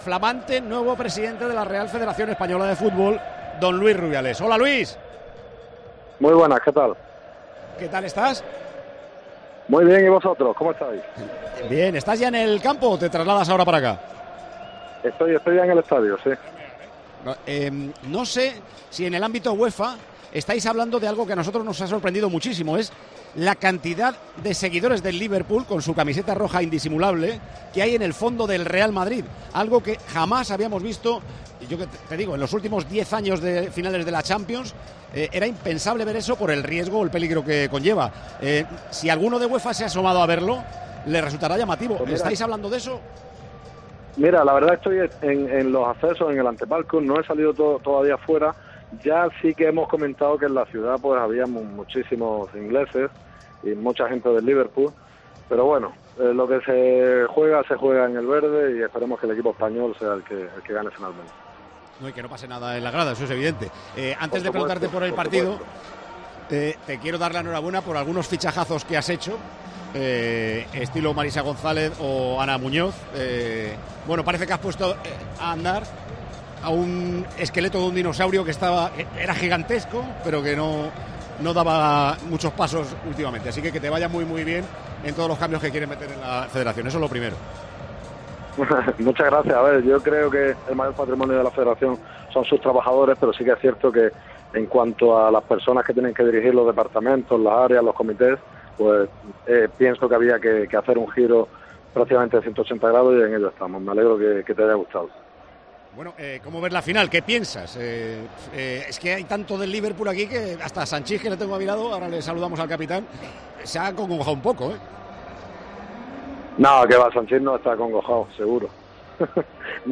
El nuevo presidente de la RFEF atendió la llamada de Tiempo de Juego desde el antepalco del Olímpico de Kiev: "Hemos comentado que en la ciudad había muchos ingleses, pero lo que se juega estará en el verde y que gane el equipo español.
Con Paco González, Manolo Lama y Juanma Castaño